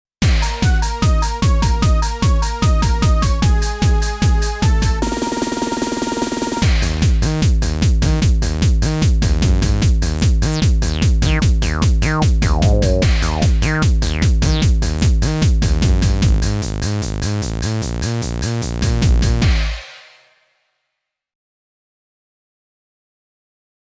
The CD insert has the message "All of the multi-Patch examples and songs were one realtime Performance on an XV-5080. No audio overdubbing was used to exceed the realtime capability of the unit."
15-techno-demo-5.mp3